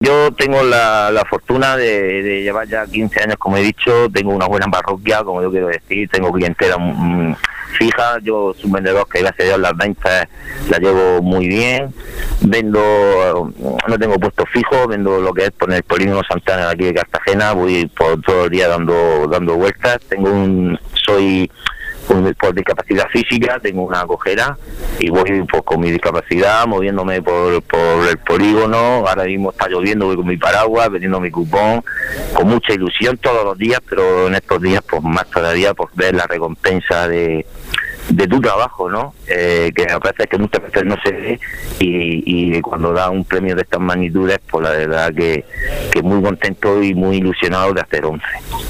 Un hombre emotivo y espontáneo, de voz cantarina.
Conversamos por teléfono, en un día lluvioso, mientras él realiza su labor, y camina despacio por su cojera fruto de un accidente laboral cuando era soldador y se cayó desde una altura de 10 metros rompiéndose las dos piernas.